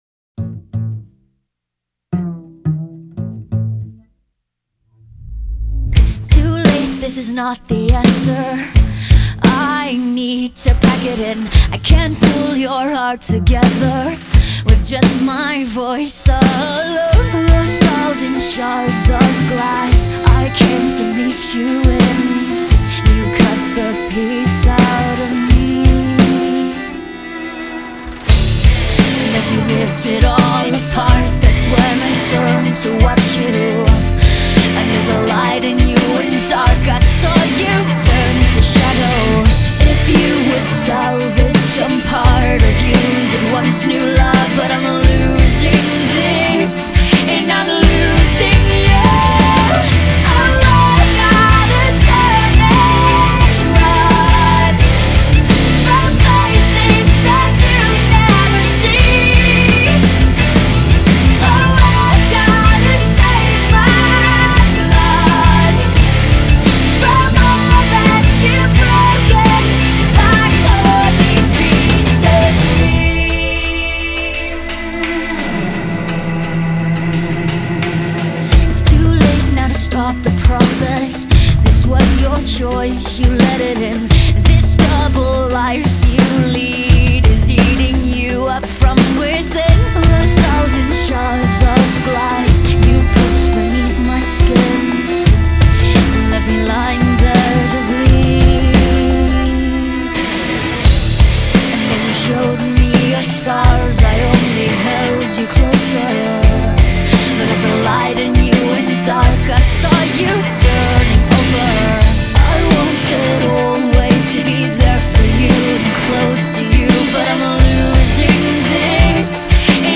Жанры: альтернативный метал, альтернативный рок,
христианский рок, пост-гранж